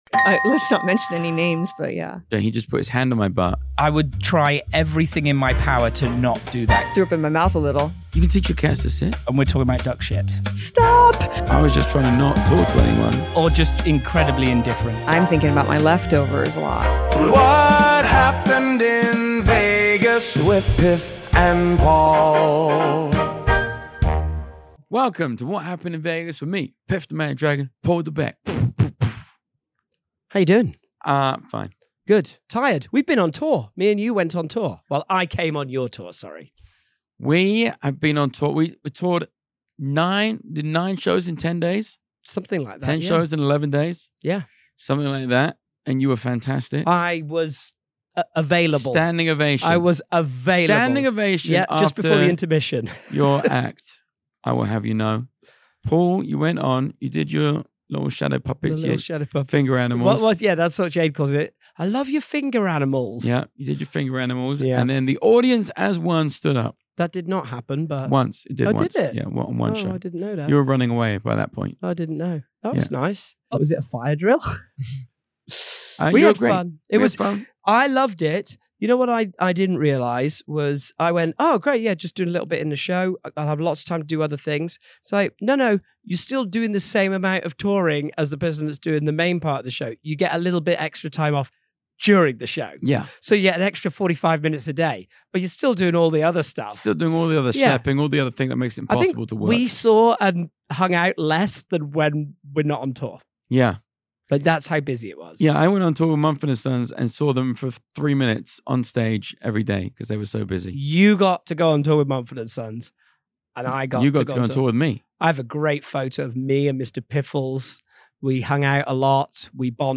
In this episode, she lets us into her world of full-blown cat obsession, reveals she’s actually been practicing magic for years, and confesses she might secretly be a world-class wizard… of Bop It. Hope you love our conversation with Dita as much as we did.